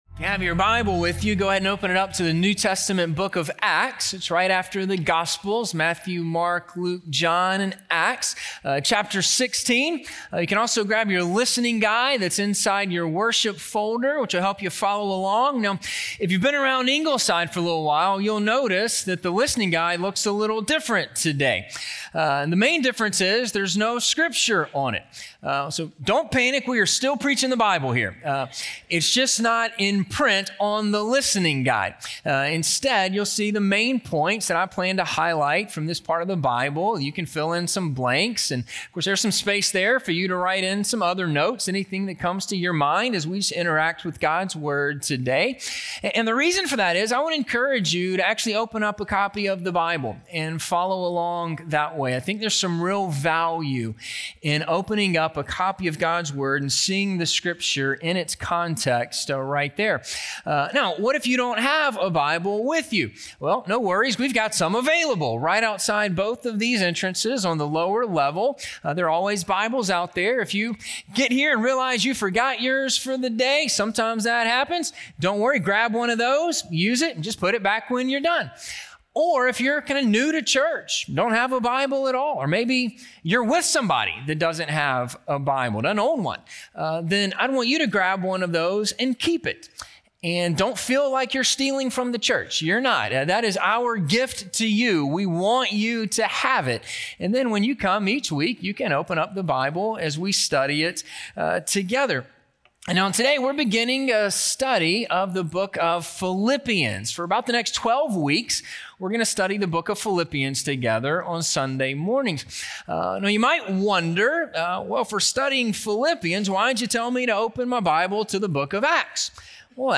The Gospel Gets to Philippi - Sermon - Ingleside Baptist Church